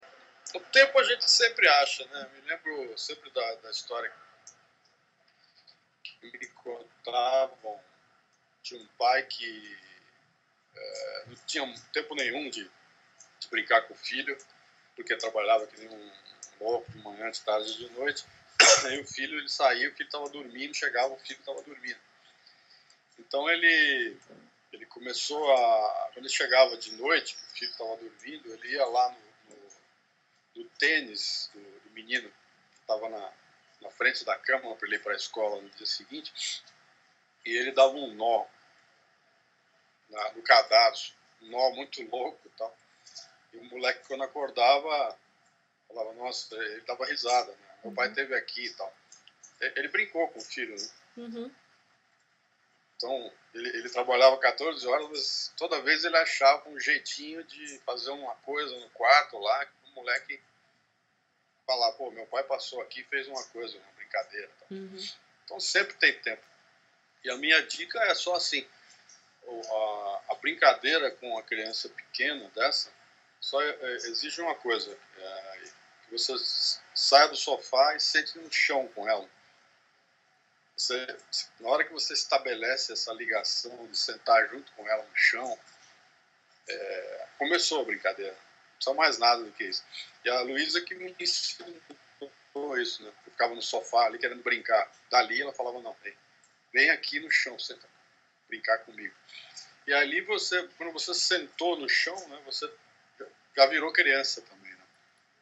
E ele também dá uma dica para os papais que acham que não sabem brincar ou não tem tempo: